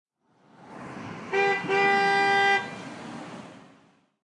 Download Vehicle Horn sound effect for free.
Vehicle Horn